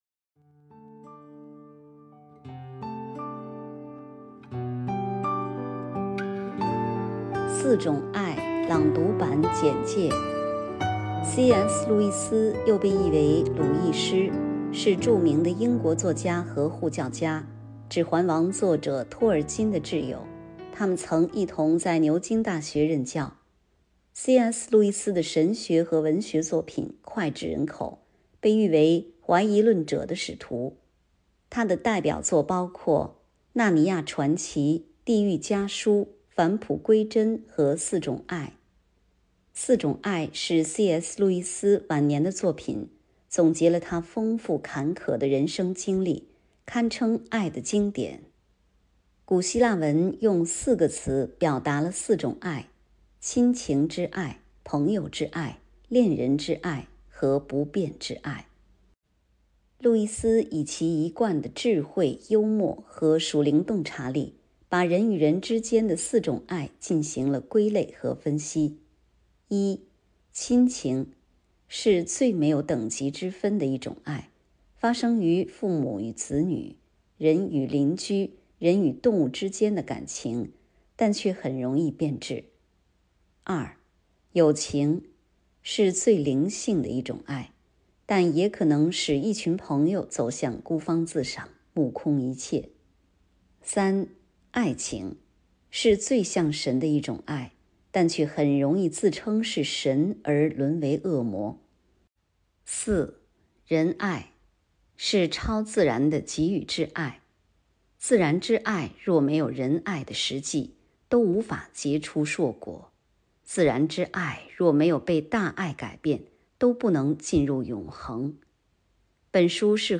《四种爱》朗读版 – 基督、使命与教会
为了忠于原文、便于朗读，本朗读版在汪咏梅中译本的基础上重新进行了校译，每章内部加了小标题，并根据朗读长度的需要进行了拆分。